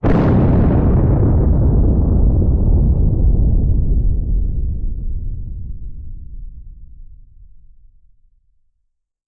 На этой странице представлены записи звуков атомного взрыва — мощные, устрашающие и заставляющие задуматься о хрупкости мира.
Короткий звук взрыва атомной бомбы в море или океане